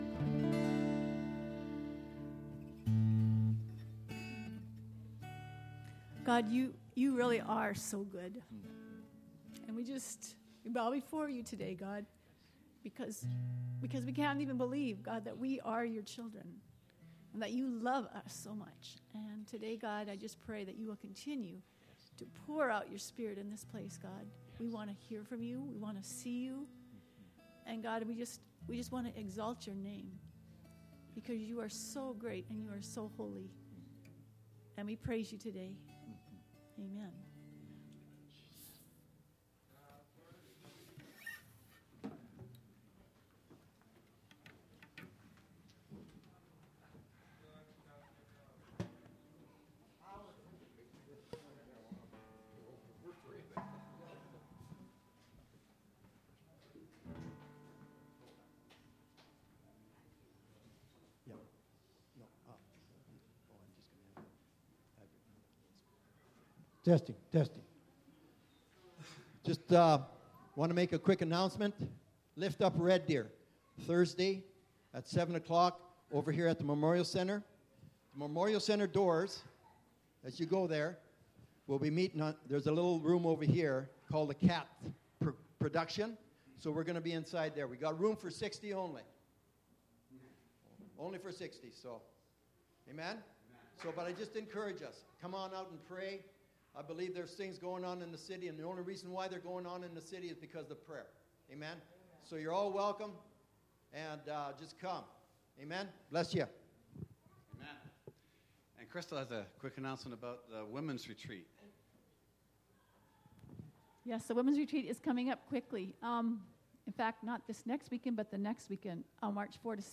Sunday service 21 feb 2016 | Potters Hands Ministries